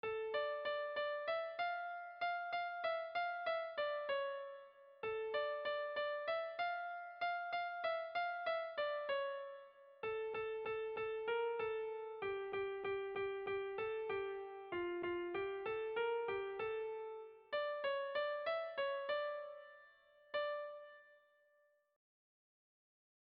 Dantzakoa
Lauko txikia (hg) / Bi puntuko txikia (ip)
AB